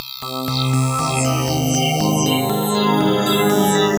Vector Lead.wav